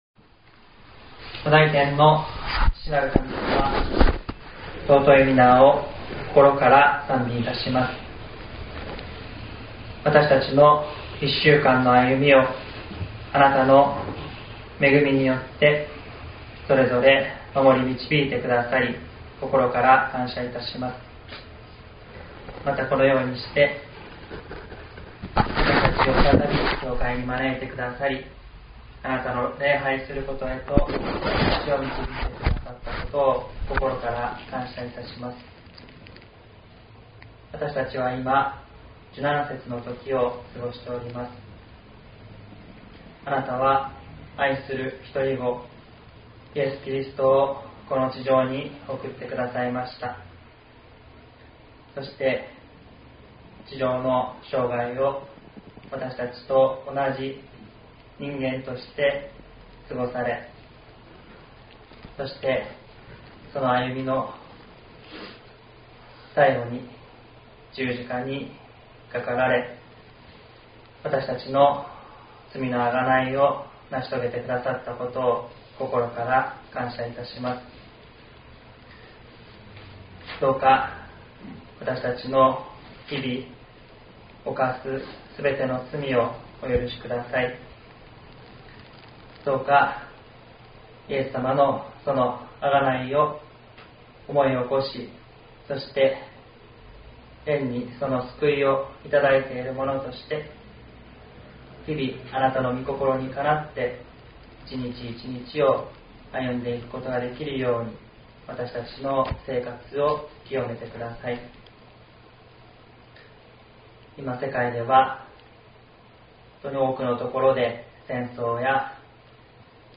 2026年03月08日朝の礼拝「福音とわたし」西谷教会
音声ファイル 礼拝説教を録音した音声ファイルを公開しています。